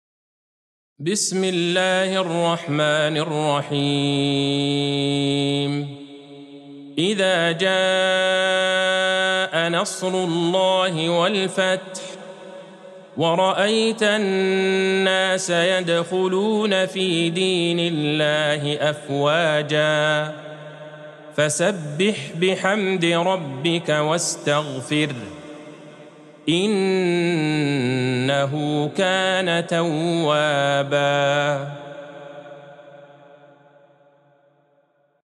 سورة النصر Surat An-Nasr | مصحف المقارئ القرآنية > الختمة المرتلة